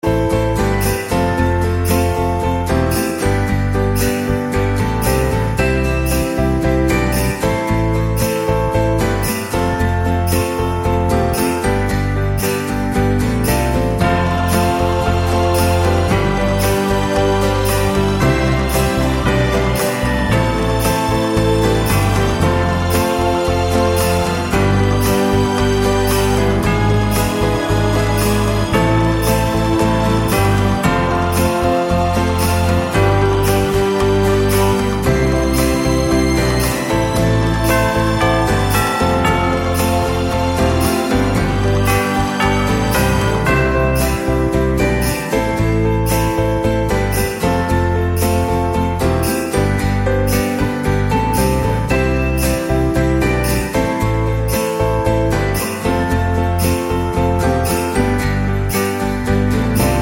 no Backing Vocals Christmas 3:27 Buy £1.50